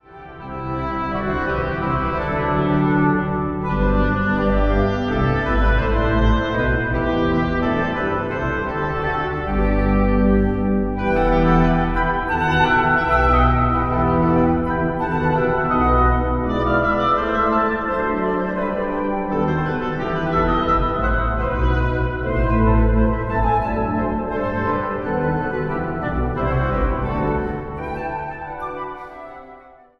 5 stemmen
Zang | Mannenkoor